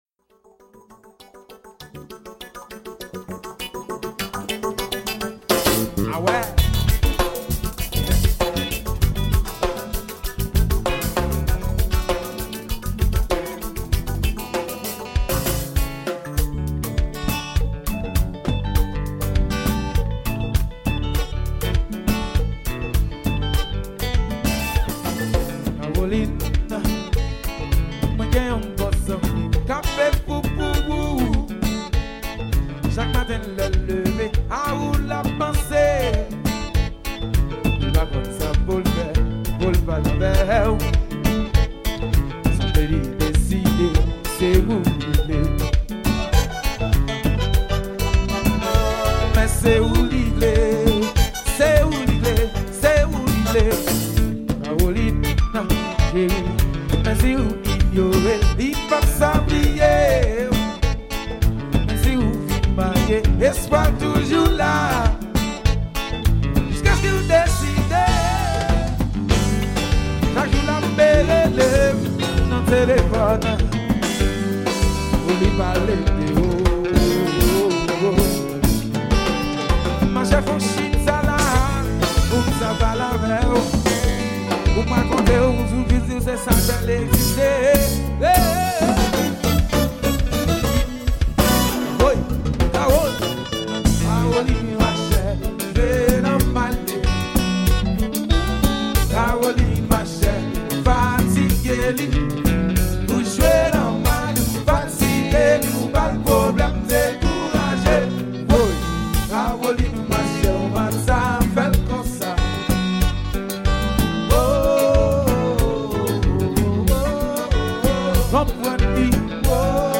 Genre: KONPA LIVE.